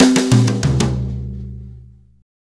Brushes Fill 69-05.wav